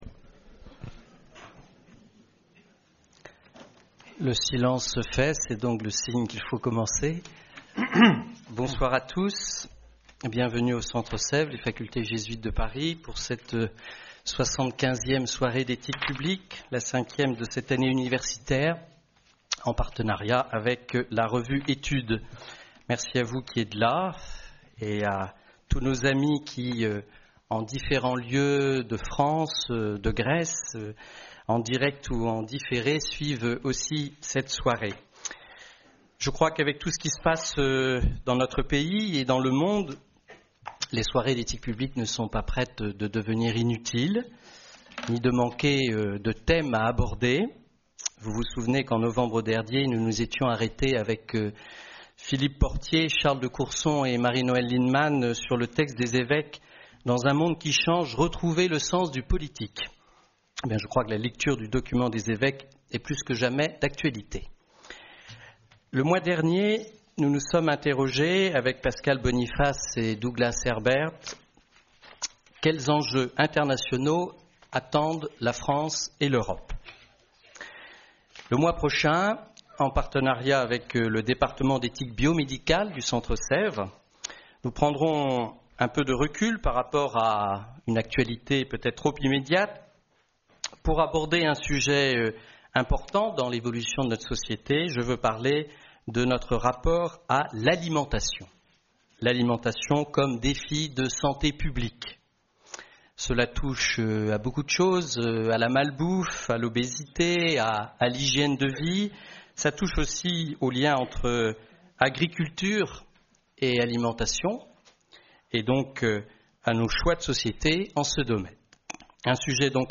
Soirée d'éthique publique